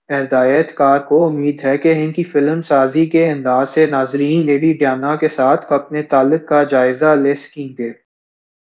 Spoofed_TTS/Speaker_13/274.wav · CSALT/deepfake_detection_dataset_urdu at main